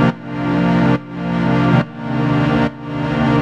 Index of /musicradar/sidechained-samples/140bpm
GnS_Pad-MiscB1:2_140-C.wav